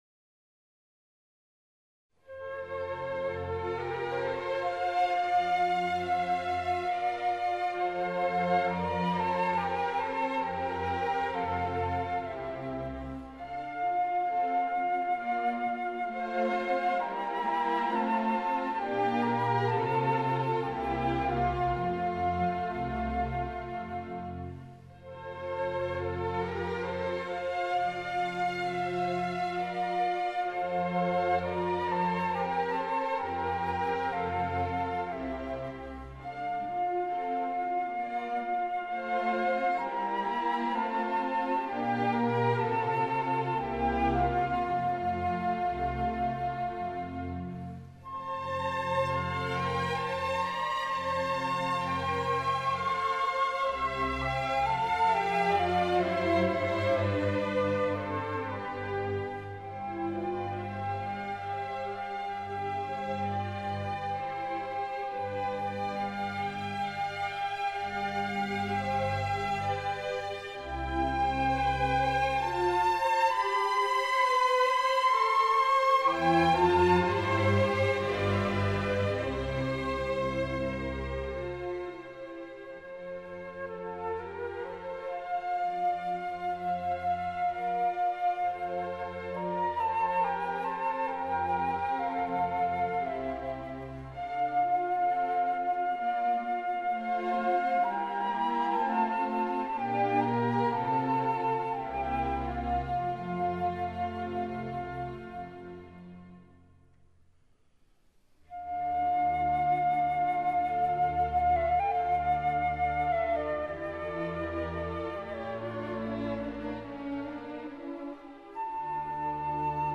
SUGERENCIAS DE REPERTORIO: TRÍO Y CUARTETO DE CUERDA
Instrumental